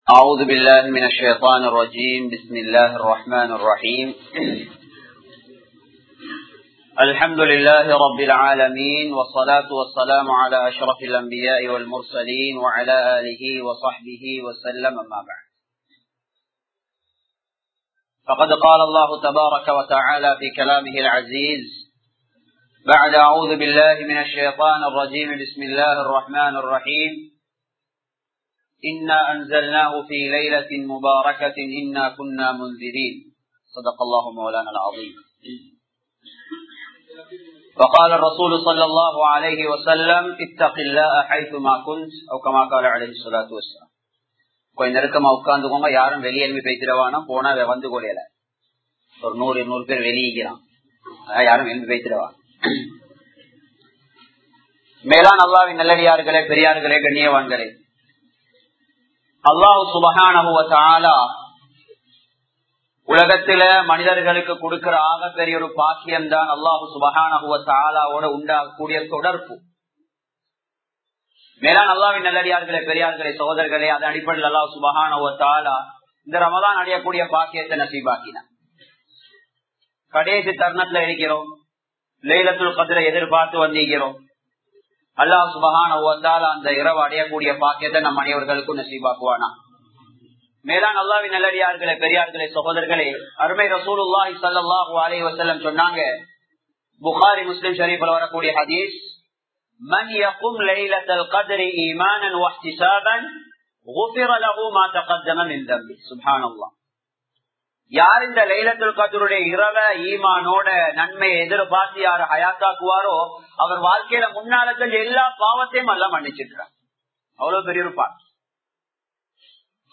Soathanaihal Sollum Paadam (சோதனைகள் சொல்லும் பாடம்) | Audio Bayans | All Ceylon Muslim Youth Community | Addalaichenai
Colombo 06, Mayura Place, Muhiyadeen Jumua Masjith